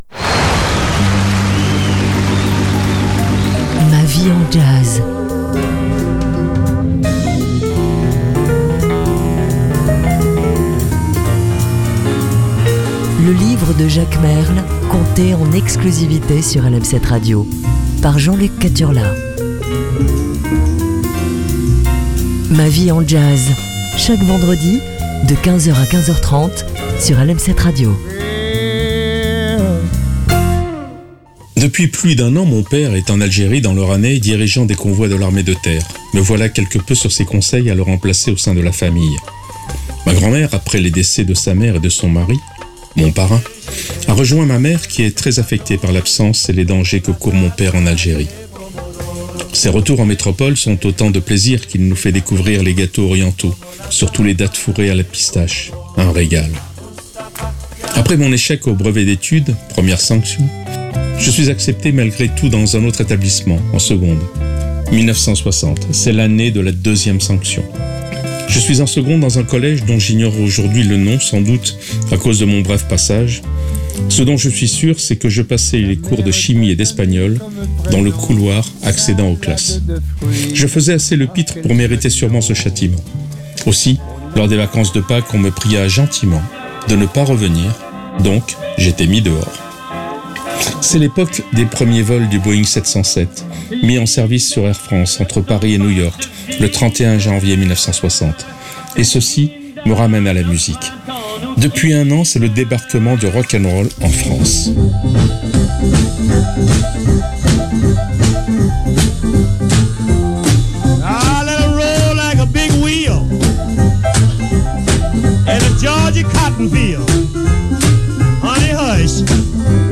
jazz & littérature